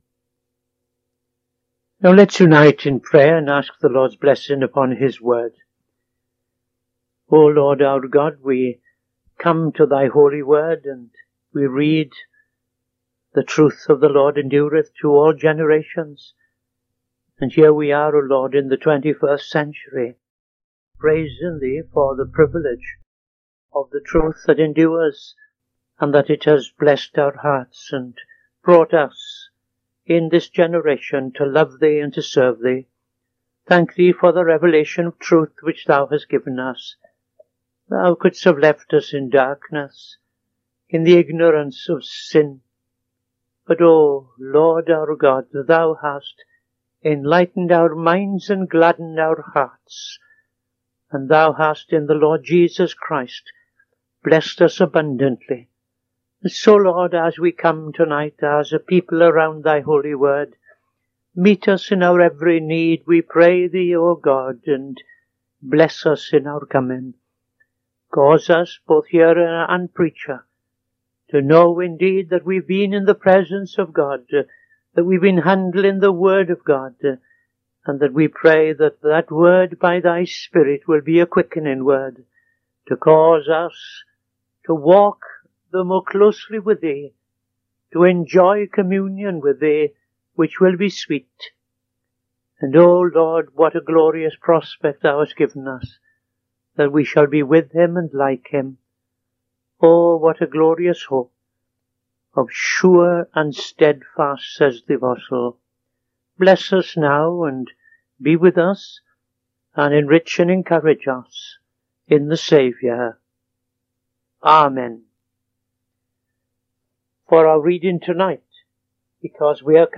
Saturday Sermon - TFCChurch
Opening Prayer and Reading II Peter 1:1-4